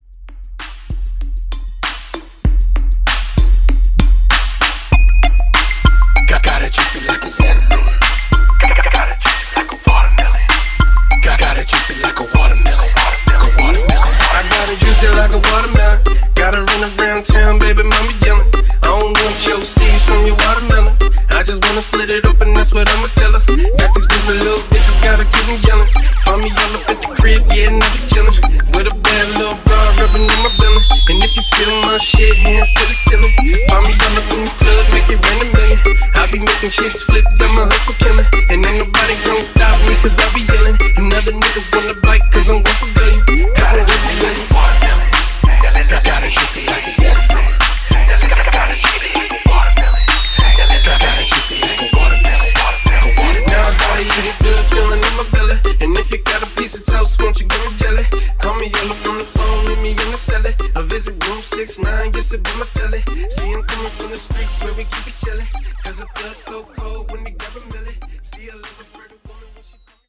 Genre: OLD SCHOOL HIPHOP
Dirty BPM: 104 Time